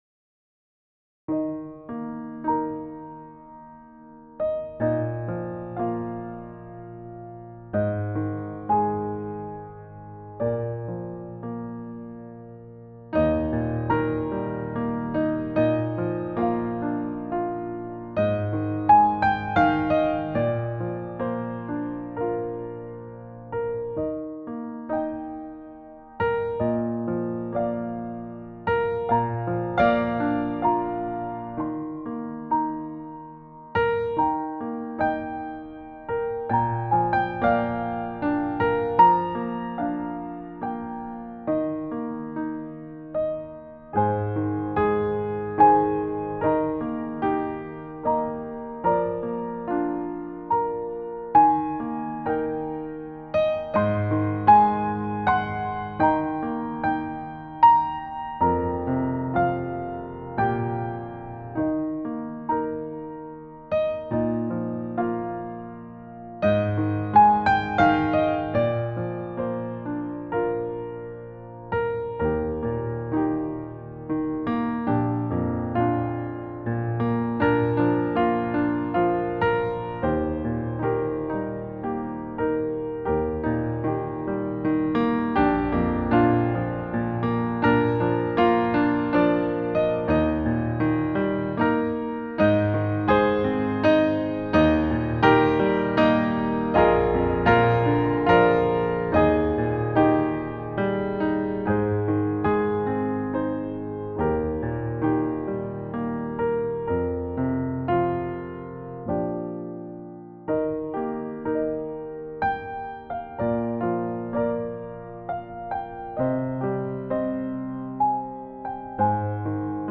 Voicing/Instrumentation: Piano Solo , Vocal Solo